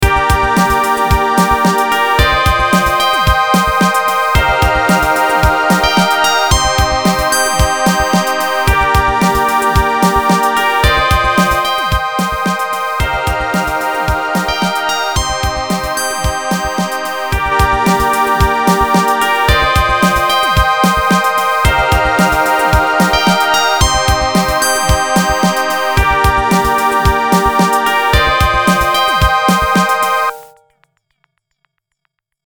Filed under: Instrumental | Comments (1)